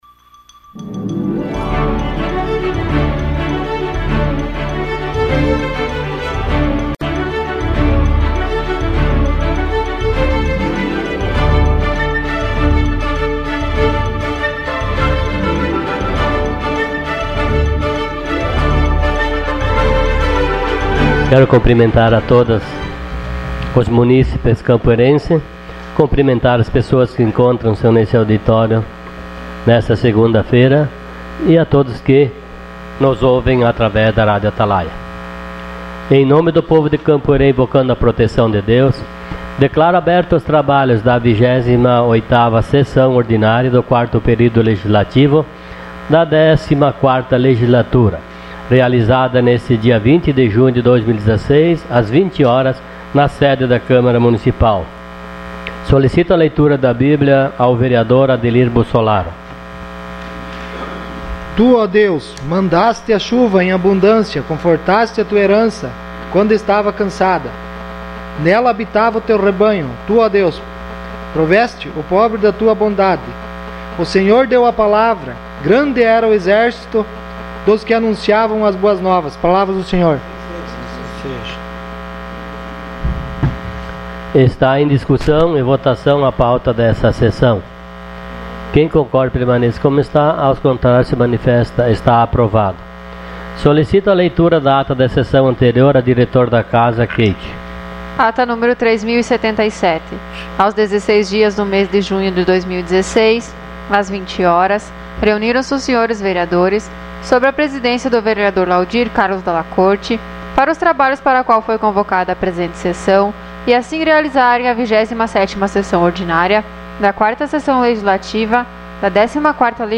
Sessão Ordinária dia 20 de junho de 2016.